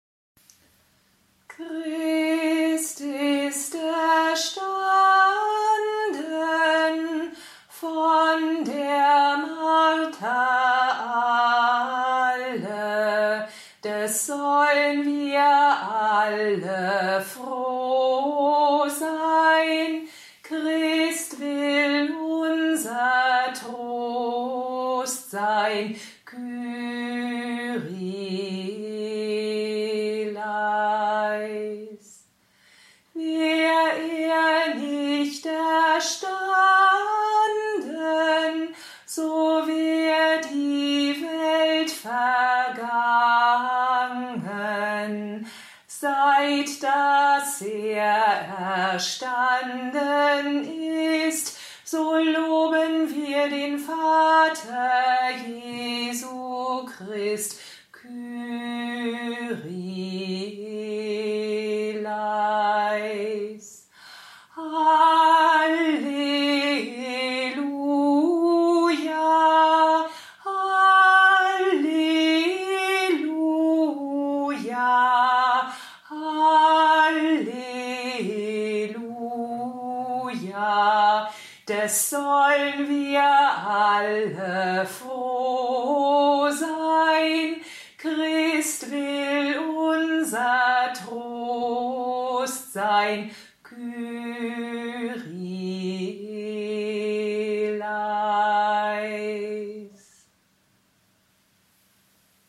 Den Choral „Christ ist erstanden“, der Teil dieser „Osternacht für Zuhause“ ist, können Sie hier anhören.